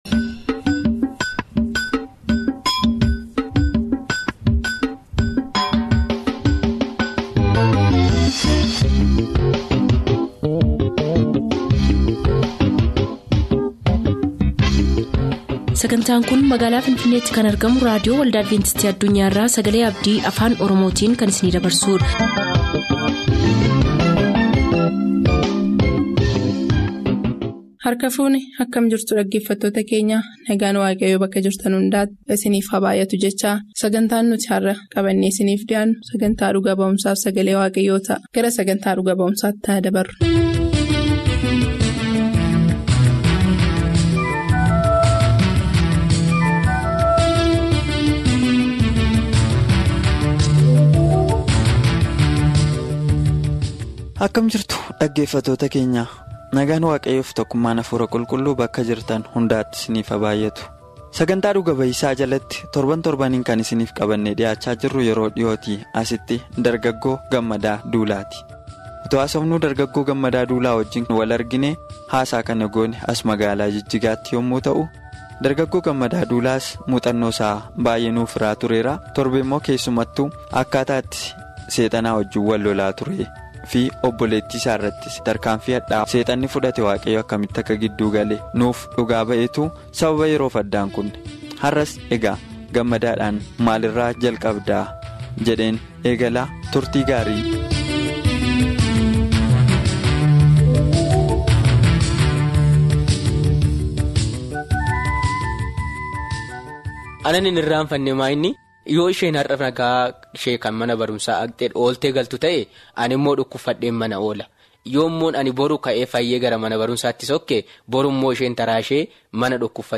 MUUXANNOO JIREENYYAA FI LALLABA. WITTNESING AND TODAY’S SERMON